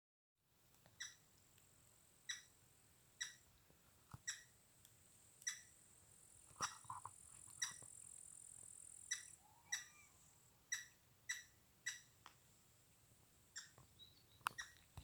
Birds -> Woodpeckers ->
Grey-headed Woodpecker, Picus canus
StatusSpecies observed in breeding season in possible nesting habitat